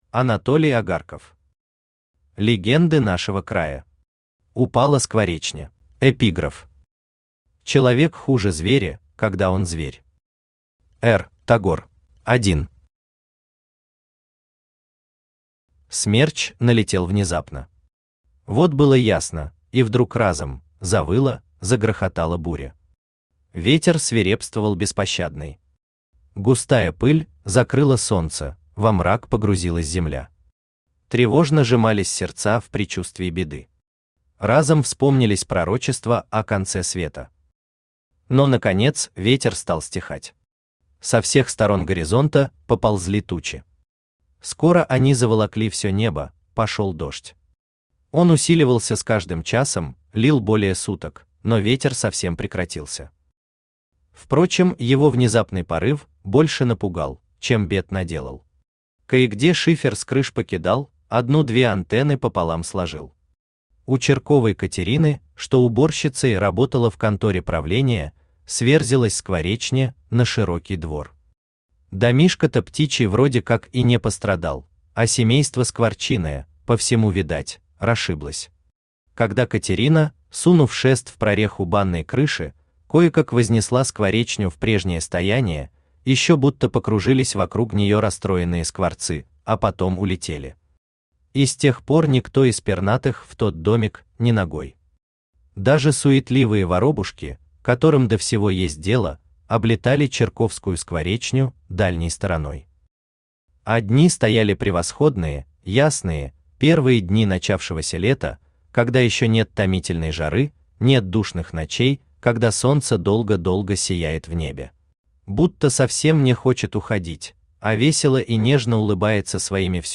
Аудиокнига Легенды нашего края. Упала скворечня | Библиотека аудиокниг
Упала скворечня Автор Анатолий Агарков Читает аудиокнигу Авточтец ЛитРес.